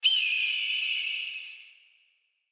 KSHMR_Animals_19_-_Hawk_2
KSHMR_Animals_19_-_Hawk_2.wav